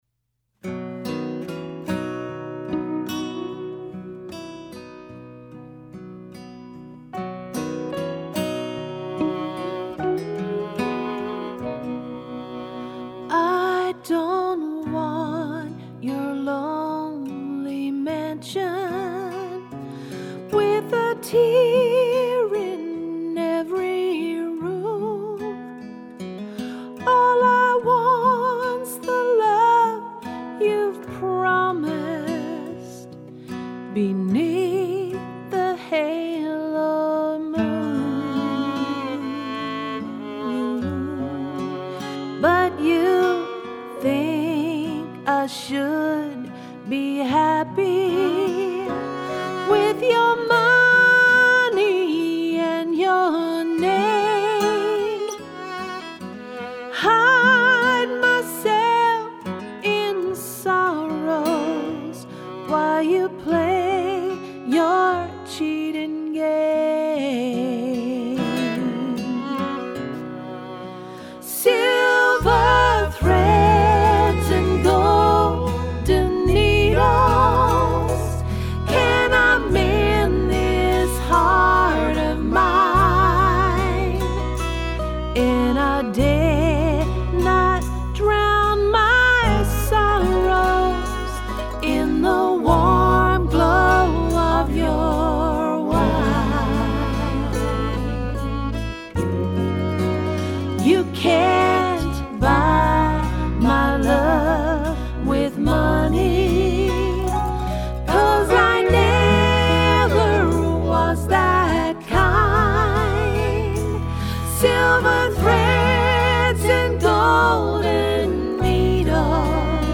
Built on strong harmonies and powerful vocals
soulful ballad